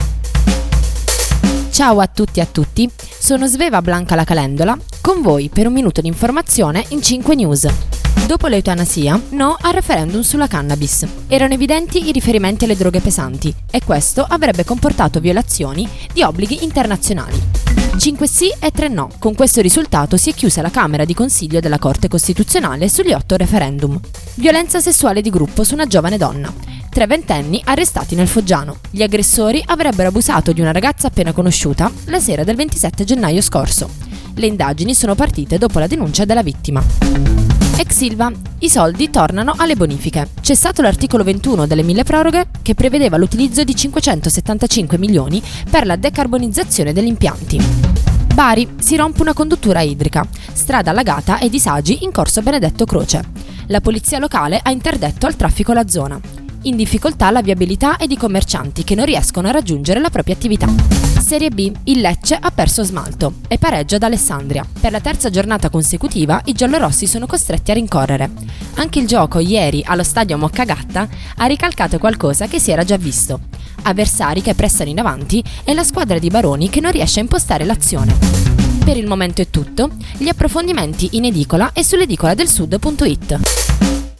Giornale radio alle ore 19